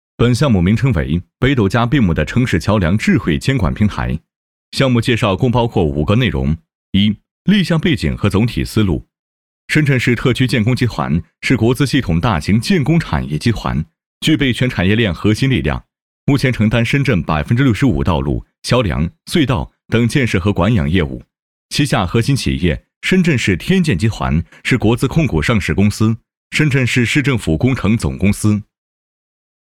男29号